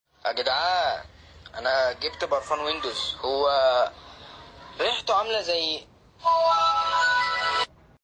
Windows11 Sound Effects Free Download